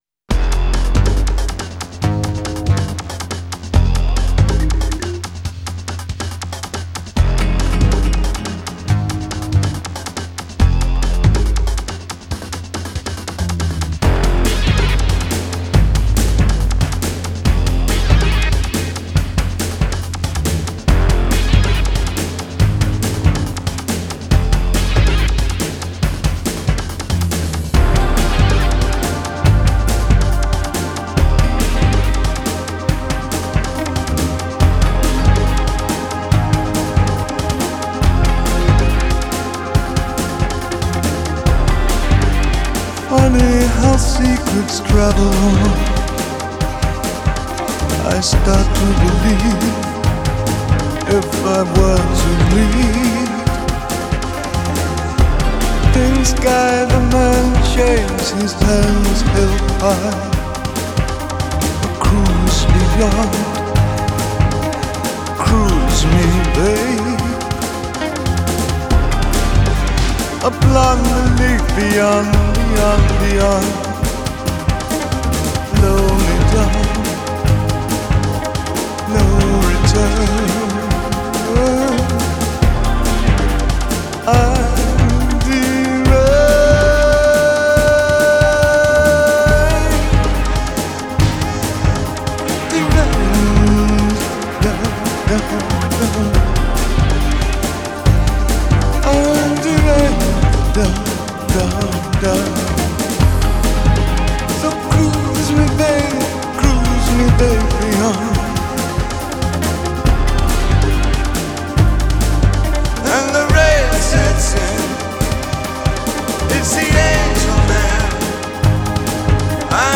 Glam Rock
موسیقی سبک راک